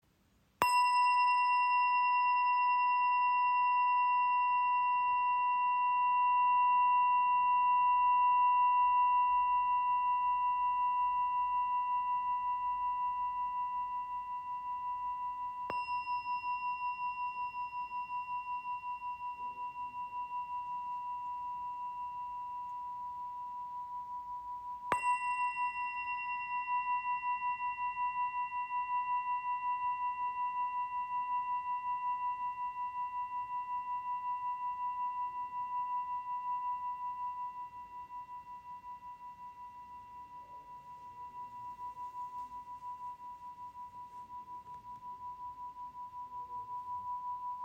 Klangglocke Terra | Grösse 7 cm | Tiefe Resonanz für Meditation und Klangarbeit
Handgeschmiedete Glocke mit warmen, erdenden Tönen und langer, klarer Resonanz.
Ihr Klang ist klar, lichtvoll und rein.
Der Klang dieser Glocken besitzt eine aussergewöhnlich lange Resonanz, vergleichbar mit der Serie Cosmos.